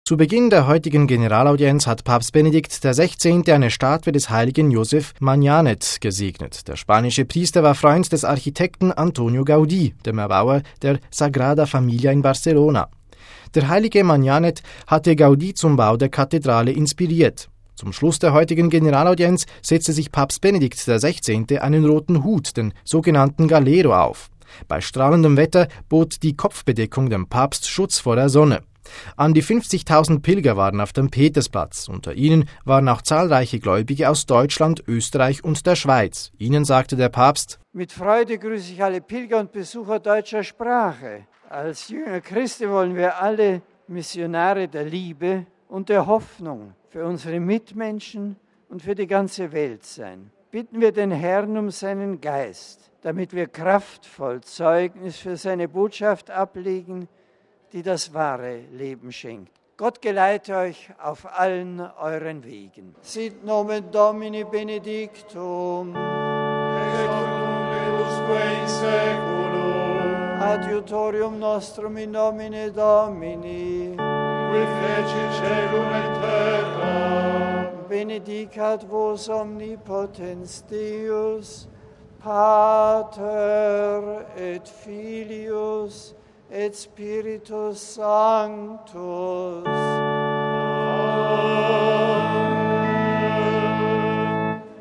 An die 50.000 Pilger waren auf dem Petersplatz; unter ihnen waren auch zahlreiche Gläubige aus Deutschland, Österreich und der Schweiz.